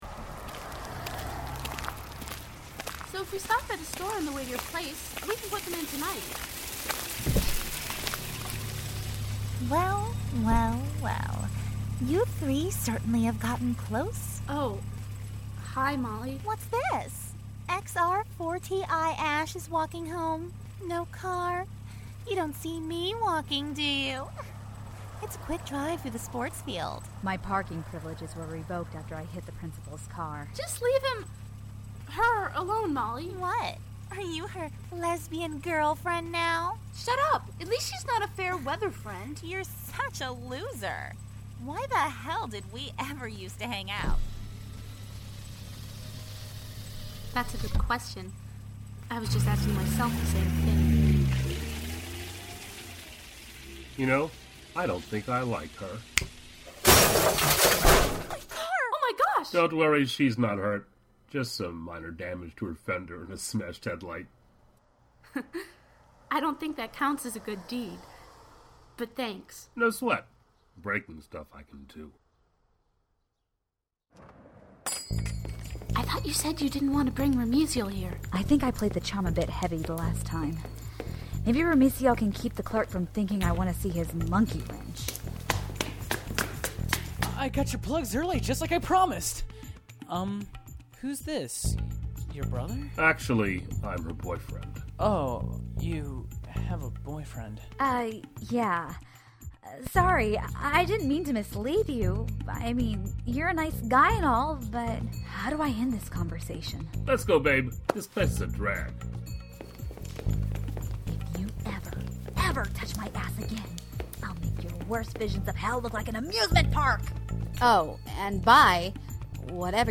Misfile - the Radioplay: episode 4